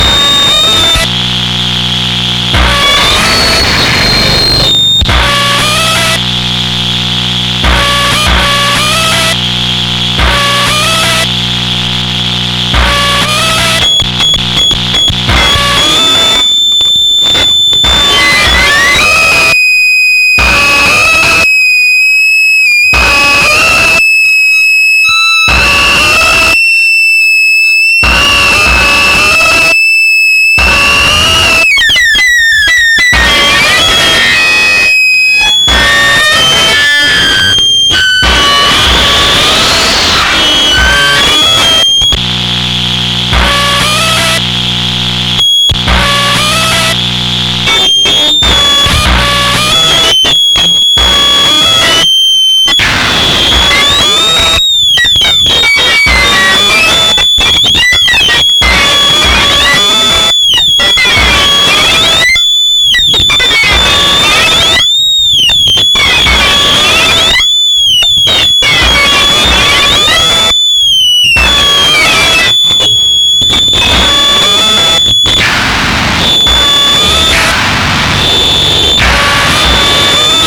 Digitally transferred in 2024 from the original tapes.
Other instruments used were guitar,
rhythm box and many other noise generators.